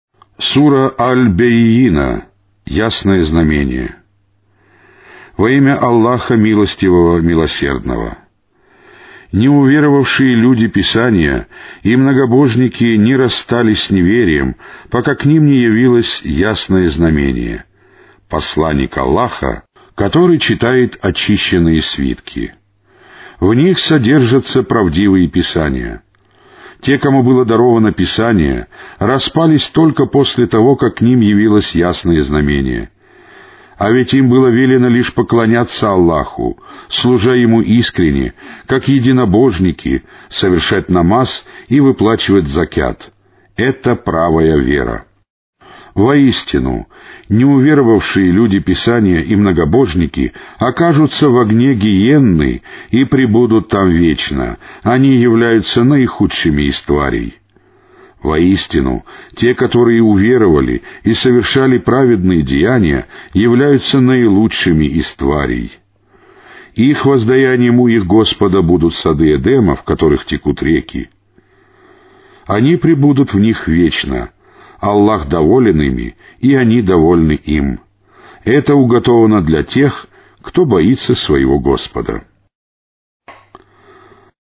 Каналы 1 (Mono).
Аудиокнига: Священный Коран